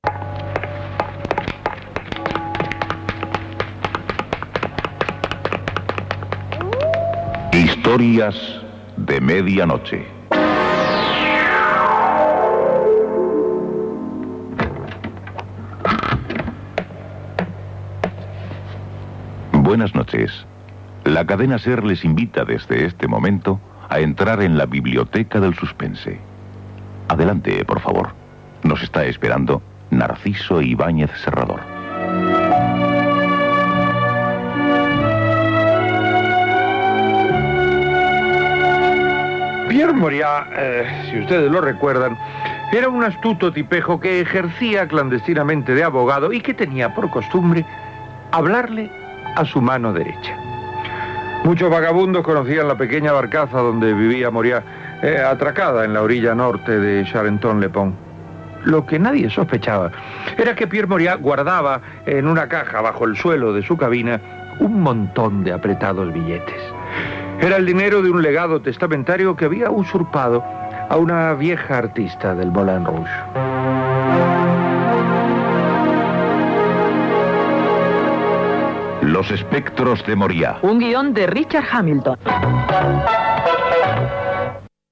Inici de la narració "Los espectros de Moryá"
Ficció